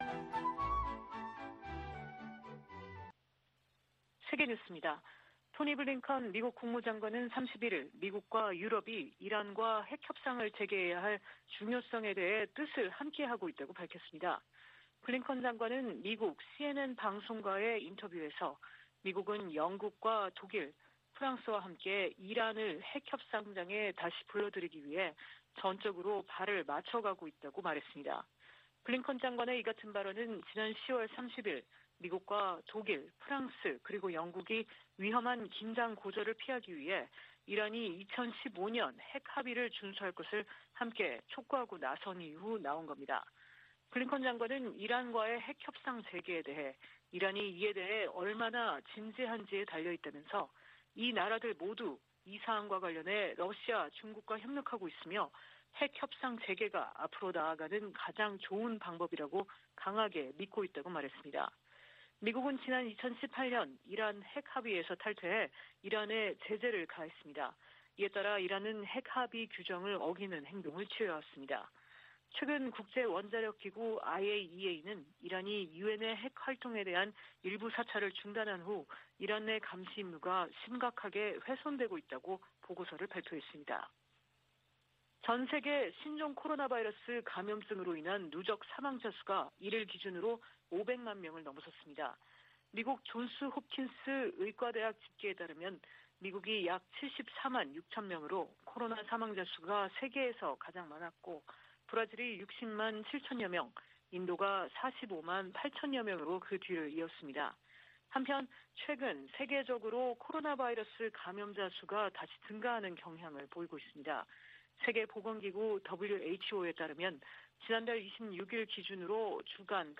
VOA 한국어 아침 뉴스 프로그램 '워싱턴 뉴스 광장' 2021년 11월 2일 방송입니다. 미 국무부가 북한의 대량살상무기(WMD) 확산 방지를 위해 활동하는 단체들에 총 1천200만 달러를 지원하기로 했습니다. 국경 봉쇄로 인한 북한의 식량난이 김정은 위원장의 지도력에 타격을 줄 수 있다고 전문가들이 분석했습니다. 미-중 갈등이 계속되는 가운데 북한과 중국이 전략적 협력을 강조하고 있습니다.